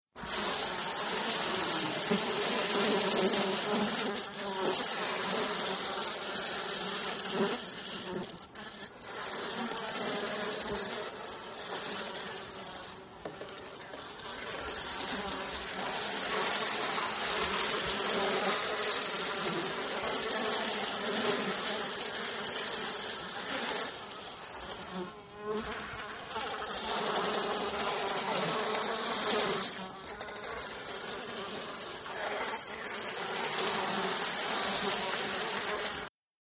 FliesCUSwarm PE660501
ANIMAL FLIES: EXT: Close up swarm of flies with intermittent bys.